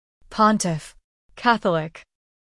最后，附上pontiff和catholic的英文发音，大家可
pontiff-catholic-pronunciation.mp3